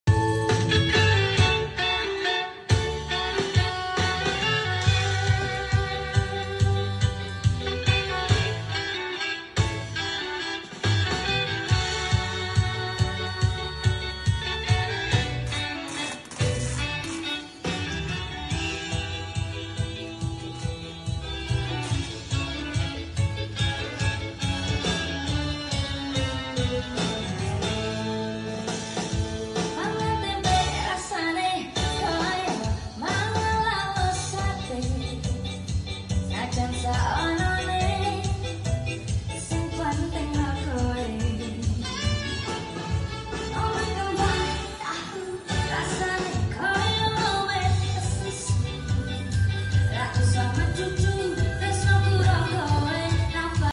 Baretone Max15H Check Sound Sound Effects Free Download